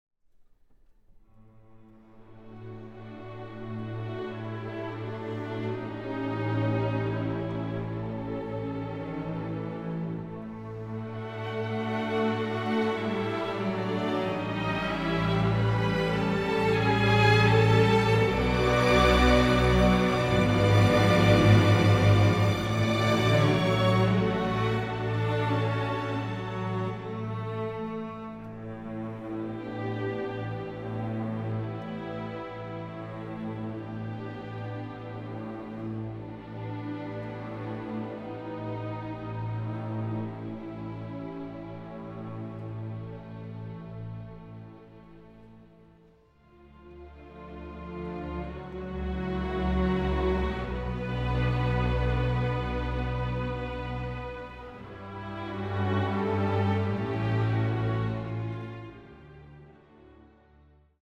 in einer Fassung für Streichorchester
Single Release - Auch in Dolby Atmos
Aufnahme: Paul-Gerhardt-Kirche Leipzig, 2025
in D-flat major